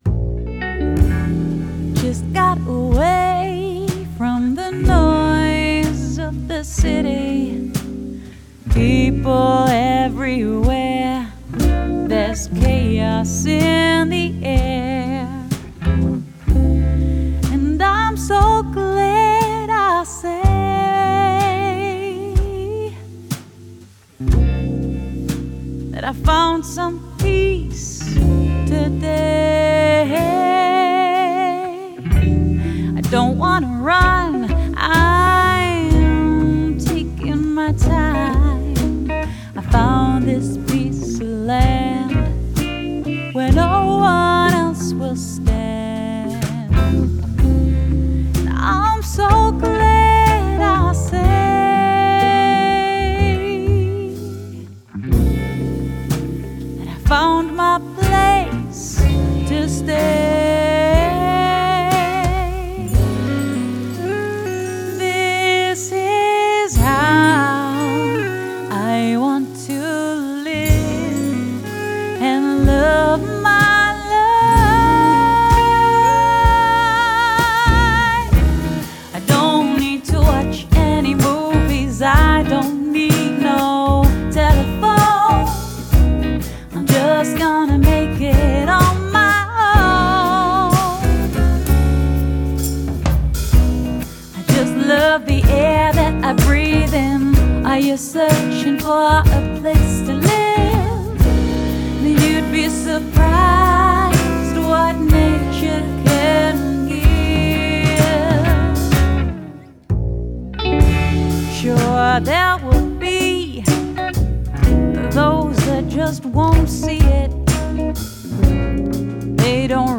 Genre: Jazz/Soul/Pop Vocals